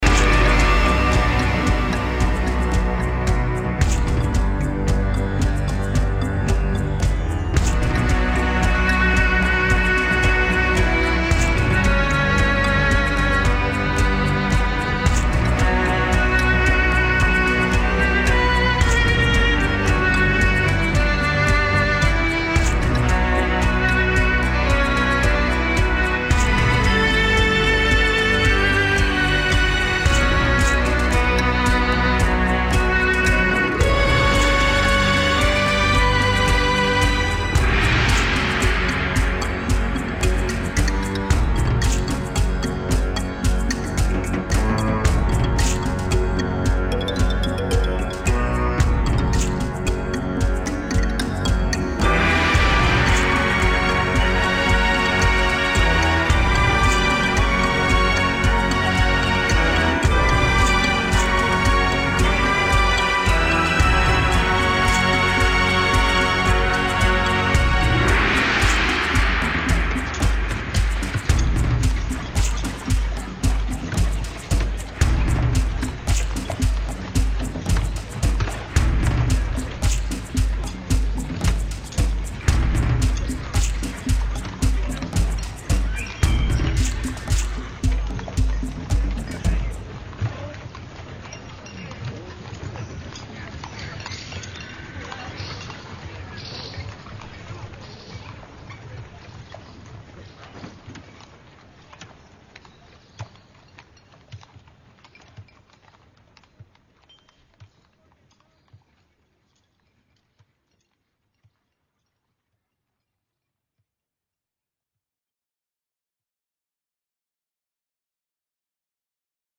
营造异族史诗见长的乐队，保持着原汁原味的乡土气息，
用了多种吉尔吉斯斯坦民间乐器，给凝重的黑金注入了新鲜感。
类型: Atmospheric/Pagan Black Metal